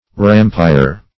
Meaning of rampire. rampire synonyms, pronunciation, spelling and more from Free Dictionary.
Search Result for " rampire" : The Collaborative International Dictionary of English v.0.48: Rampire \Ram"pire\ (r[a^]m"p[imac]r), n. A rampart.